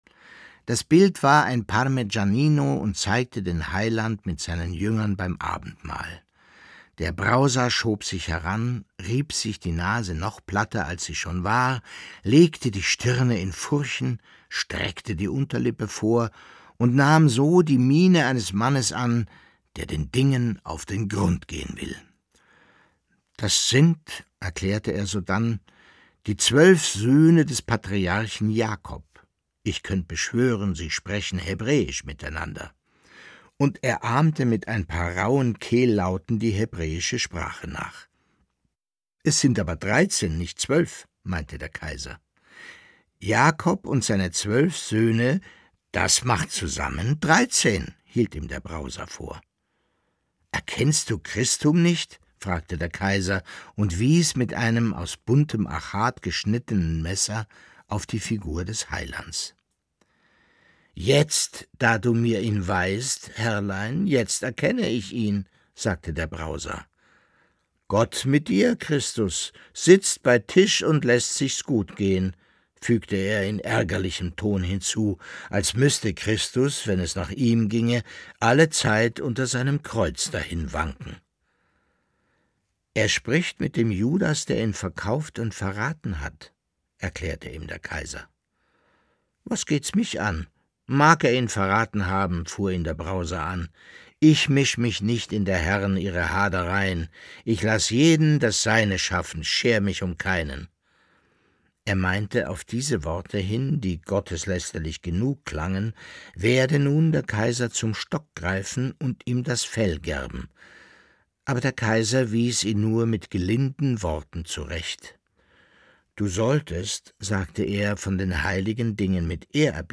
Leo Perutz: Nachts unter der steinernen Brücke (17/25) ~ Lesungen Podcast